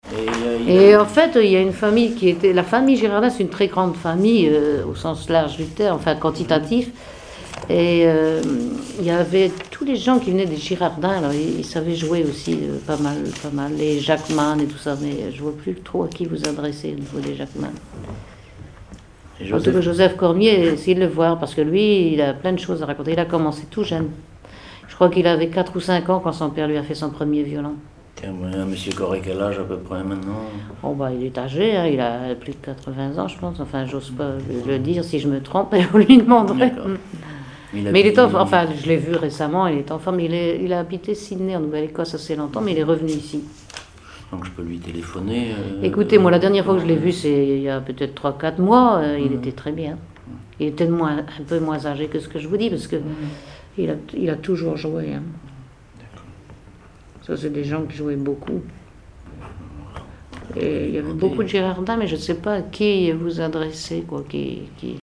Conversation sur les musiciens de Saint-Pierre et Miquelon
Catégorie Témoignage